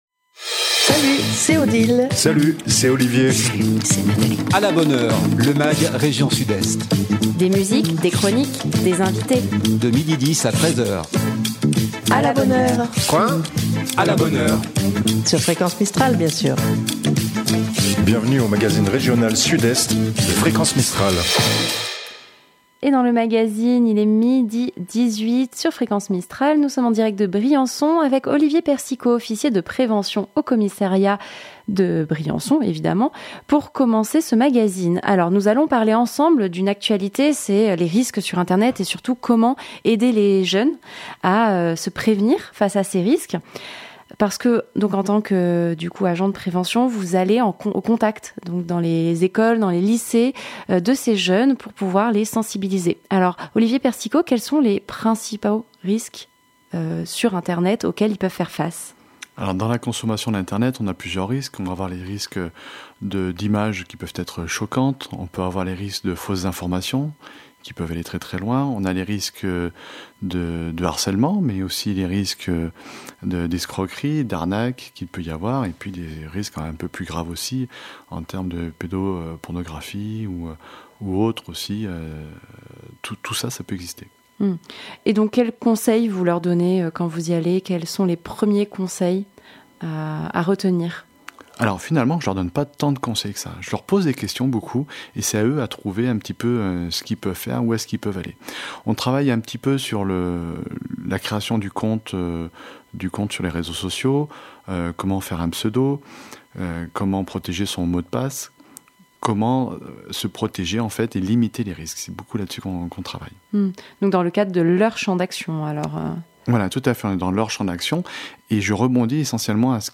des invité.e.s en direct